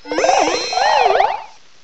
sovereignx/sound/direct_sound_samples/cries/eldegoss.aif at master